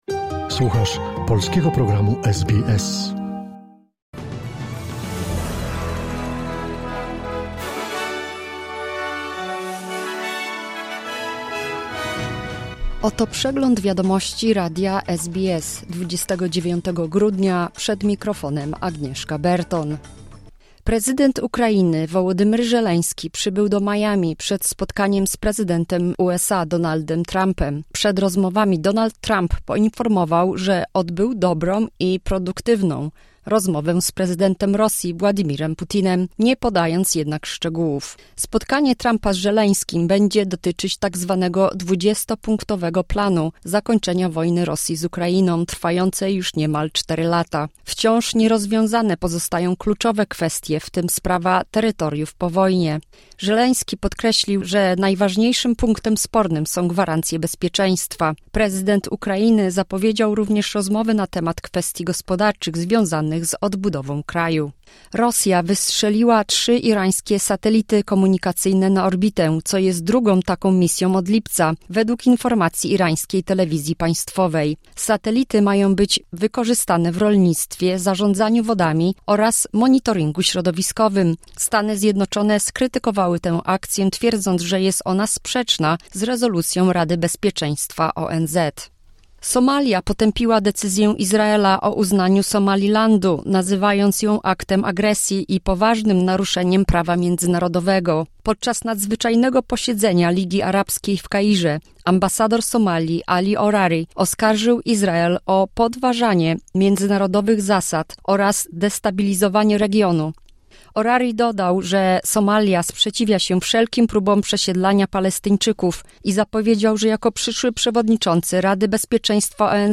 Wiadomości 29 grudnia SBS News Flash